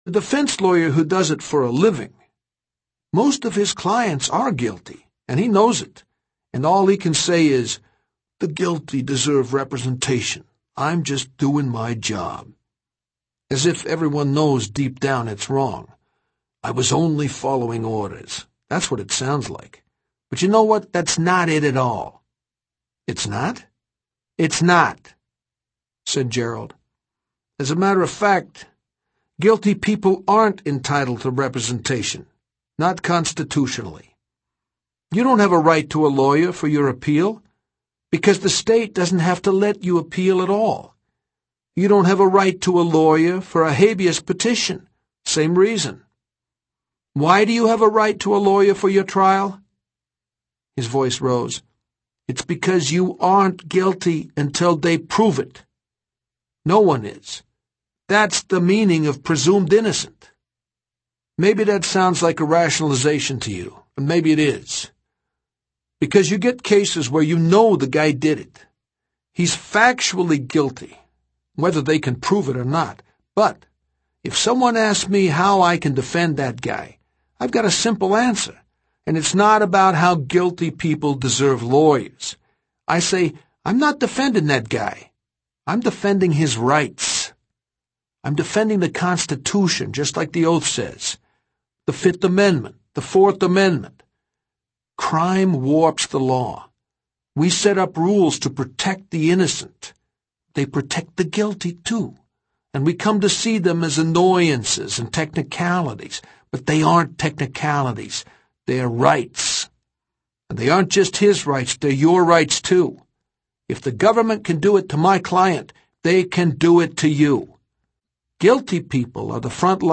I’ve just finished listening to an otherwise unremarkable audio book called In the Shadow of the Law by Kermit Roosevelt.
I’m presenting the passage in audio form because the narrator does such as great job of portraying the passion of the character and the issue.